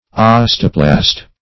Search Result for " osteoplast" : The Collaborative International Dictionary of English v.0.48: Osteoplast \Os"te*o*plast\, n. [Osteo- + Gr. pla`ssein to form.]